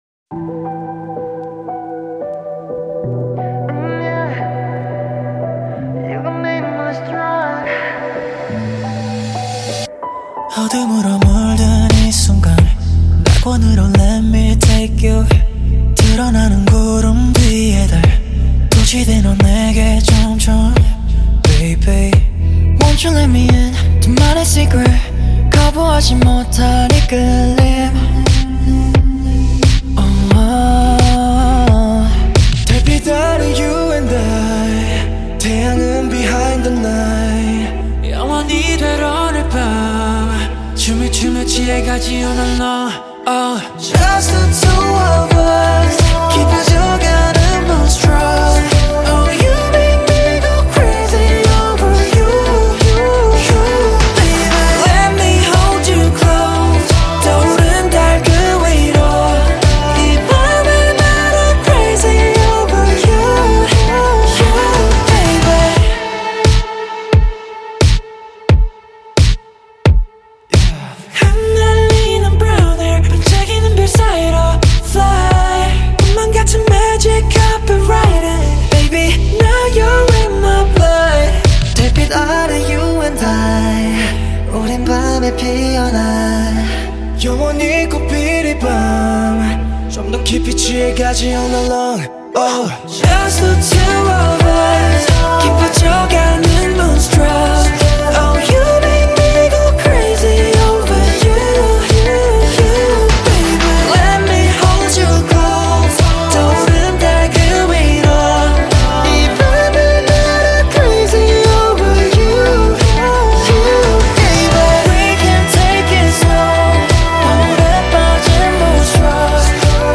KPop
Label Dance